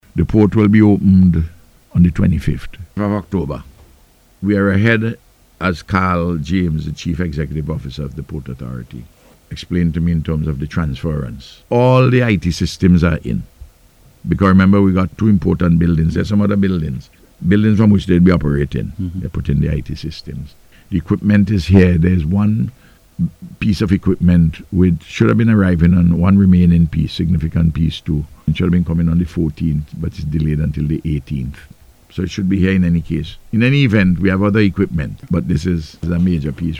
He made this disclosure during the Face to Face programme aired on NBC Radio.